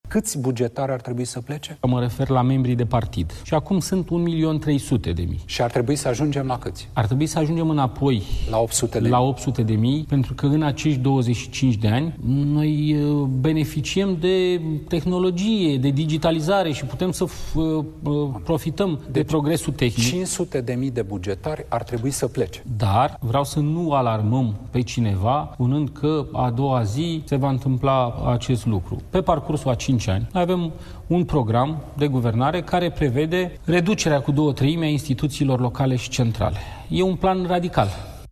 Într-un interviu pentru Digi 24, el a precizat că există mai multe variante de lucru, între care şi alegerile anticipate.